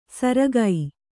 ♪ saragai